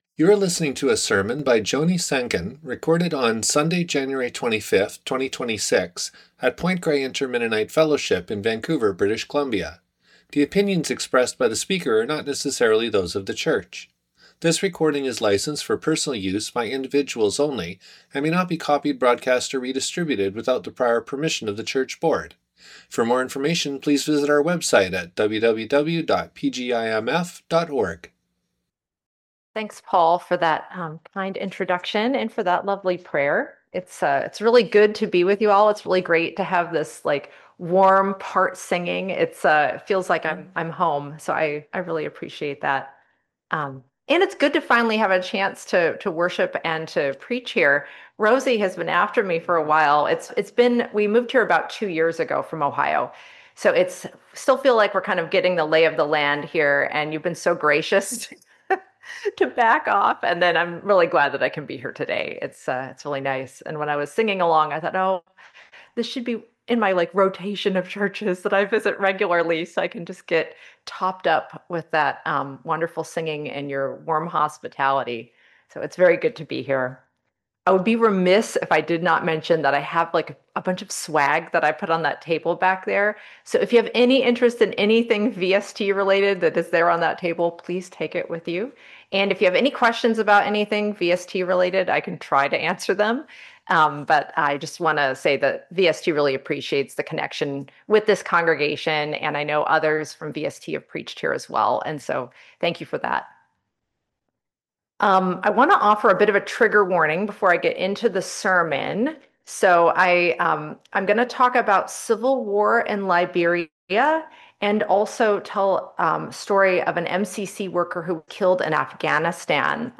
Sunday morning service sermon audio recordings from the Point Grey Inter-Mennonite Fellowship.